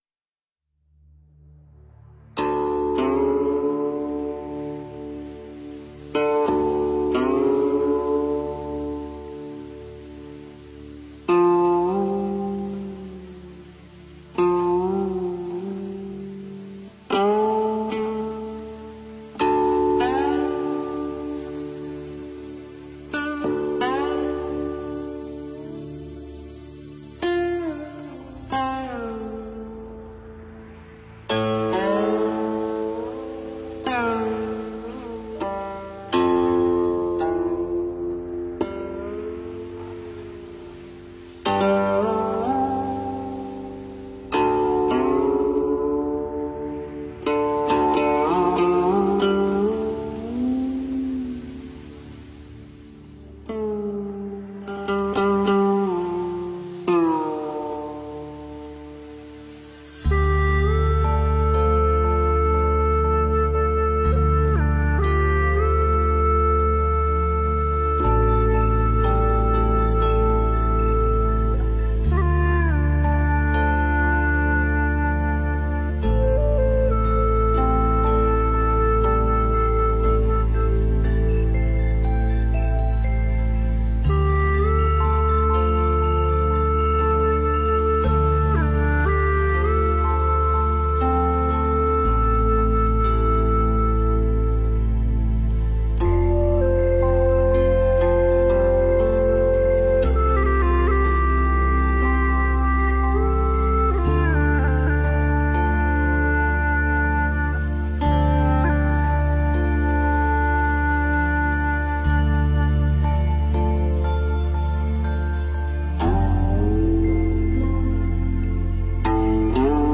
养心禅曲--佚名 冥想 养心禅曲--佚名 点我： 标签: 佛音 冥想 佛教音乐 返回列表 上一篇： 105.如何解脱生老病死--佚名 下一篇： 古琴佛曲--佚名 相关文章 楞严心咒--新韵传音 楞严心咒--新韵传音...